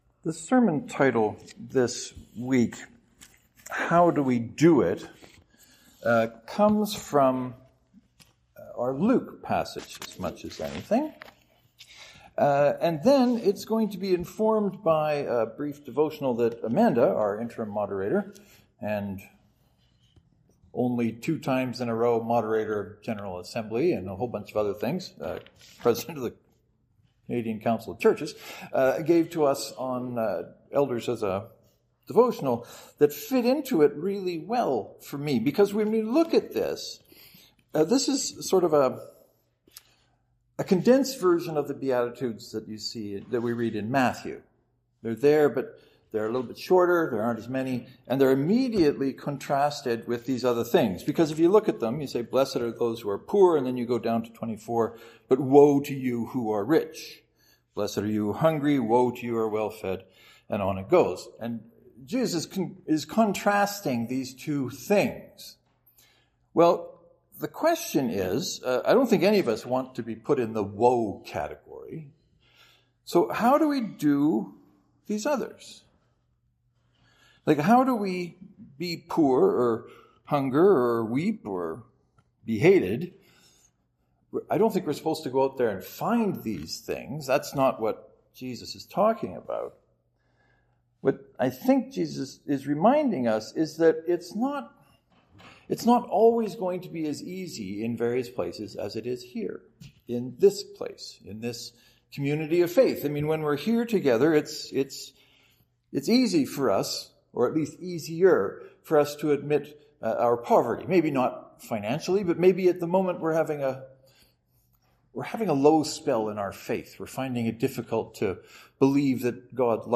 St. Mark’s Presbyterian (to download, right-click and select “Save Link As .